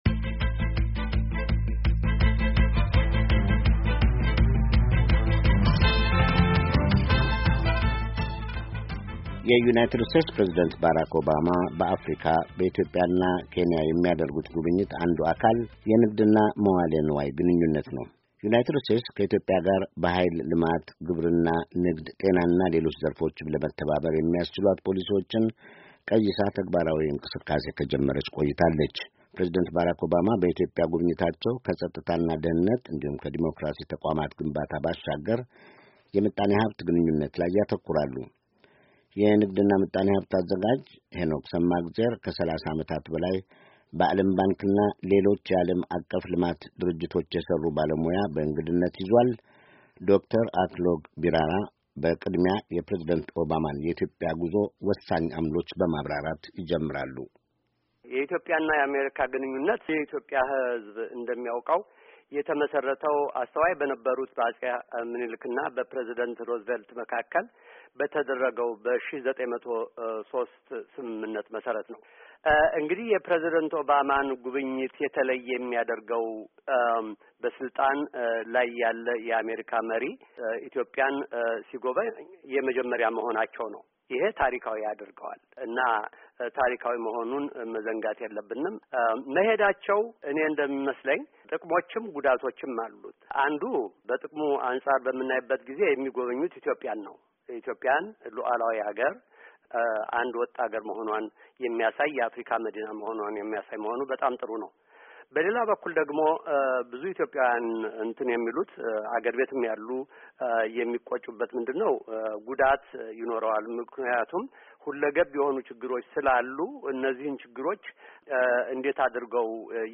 የፕሬዚደንት ኦባማ የኢትዮጵያ ጉዞ ከምጣኔ ሃብት ግንኙነት አንፃር- ባለሞያው ይተነትናሉ